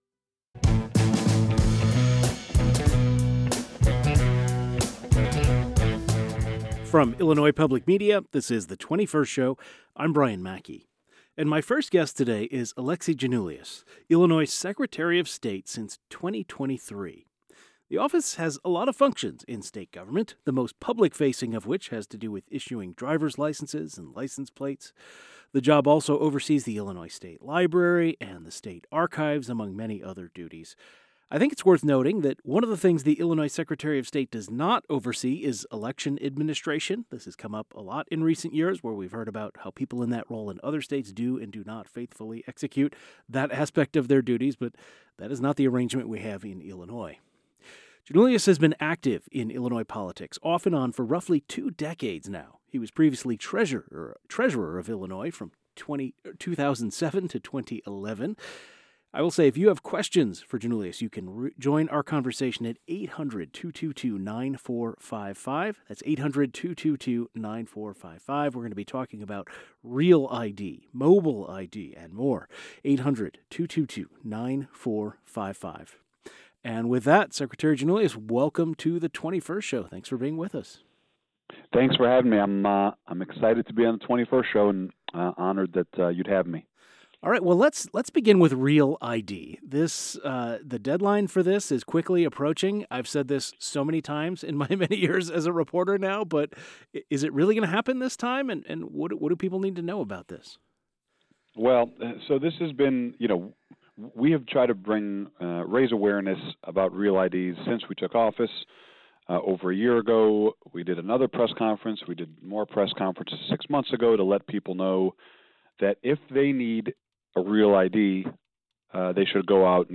We talked with Illinois Secretary of State about all of this.